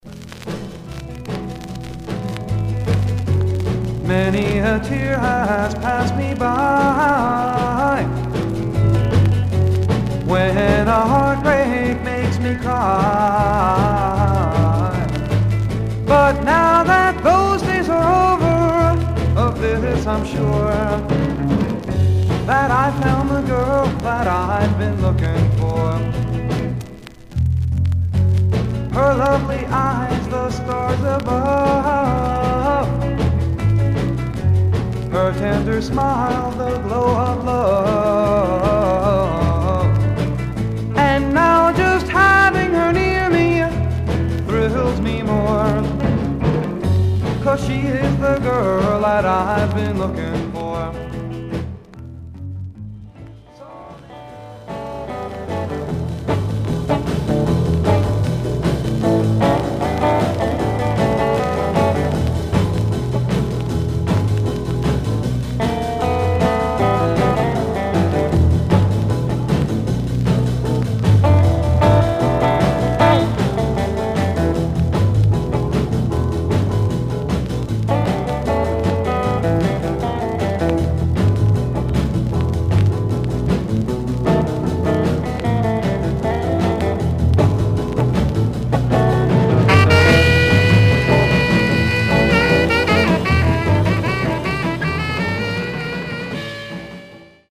Mono
Garage, 60's Punk